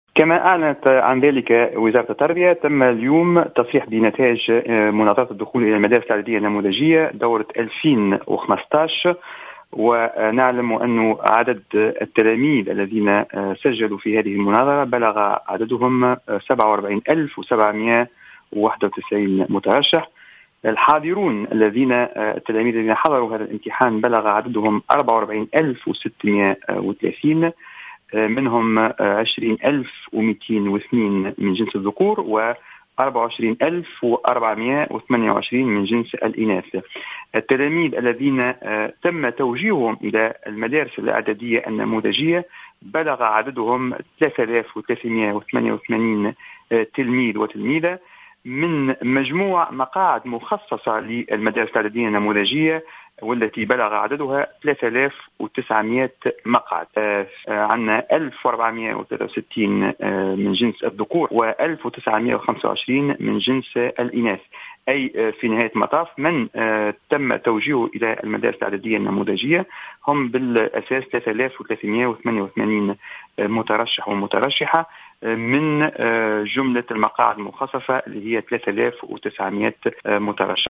أكد أكد المدير العام للامتحانات بوزارة التربية عمر الولباني في تصريح لجوهرة "اف ام" أنه تم اليوم التصريح بنتائج مناظرة الدخول الى المدارس الاعدادية النموذجية دورة 2015 .